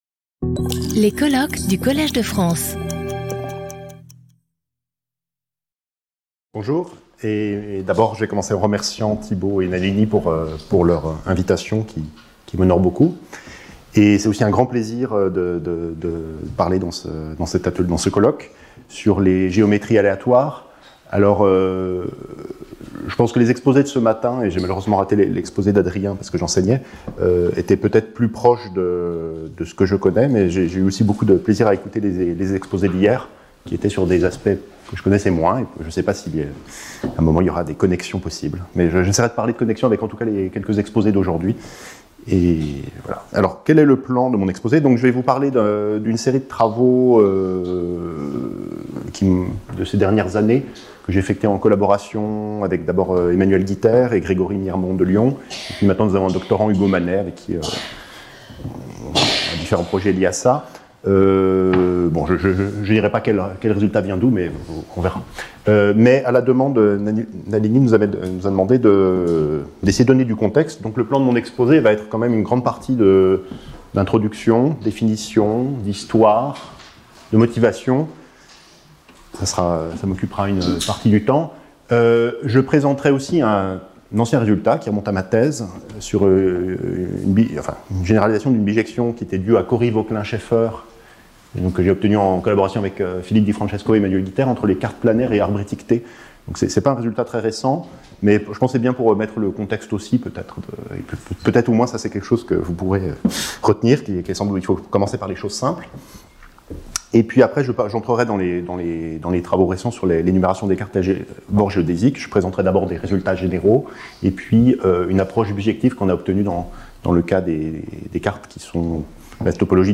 Symposium